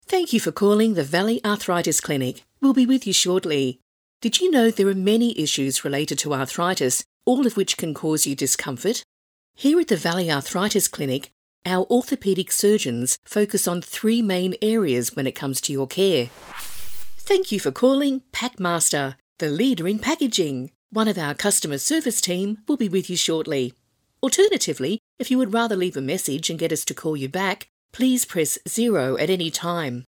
• On Hold
• Warm
• Fun
• Mature